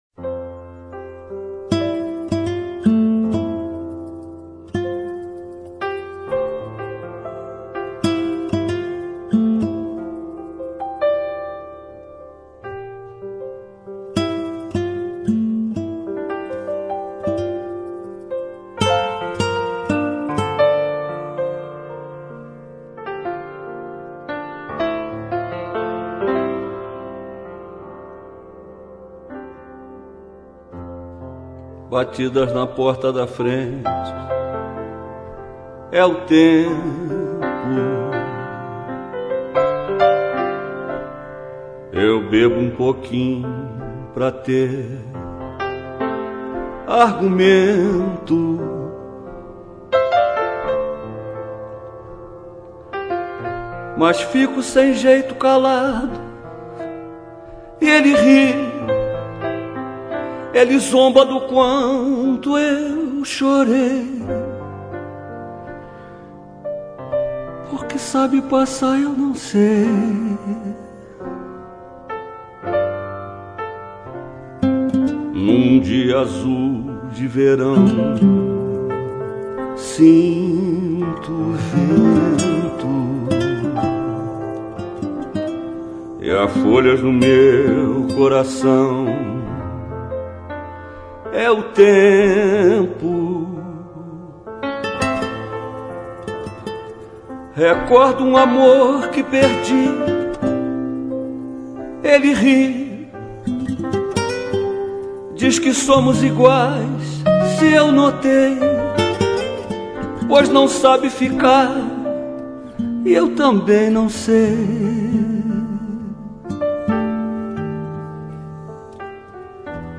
Piano E Violao